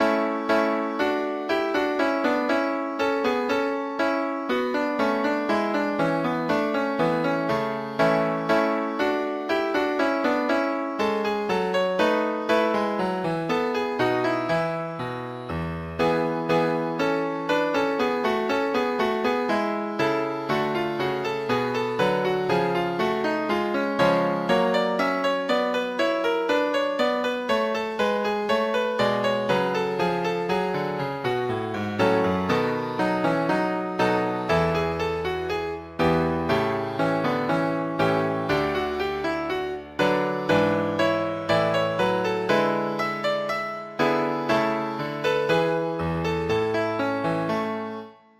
Classical Bach, Johann Sebastian English Suite II:Bourree II BWV 807 Piano version
Free Sheet music for Piano
bach-english-suite-2-bourree-2.mp3